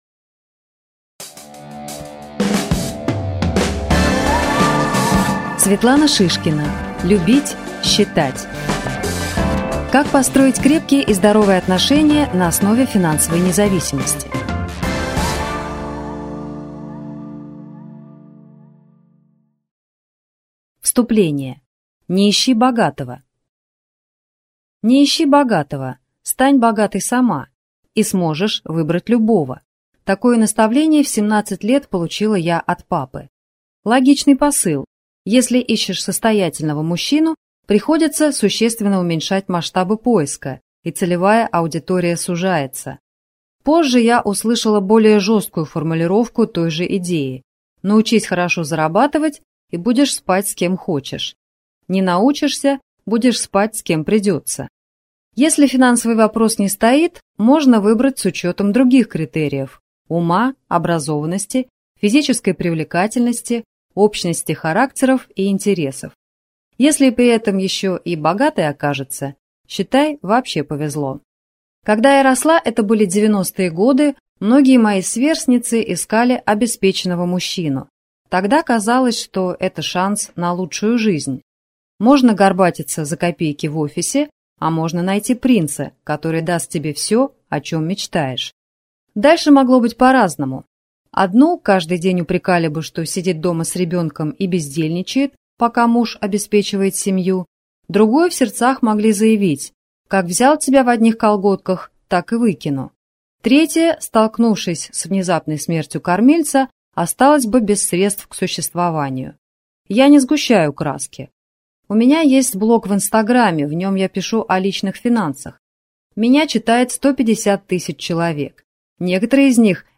Аудиокнига Любить. Считать | Библиотека аудиокниг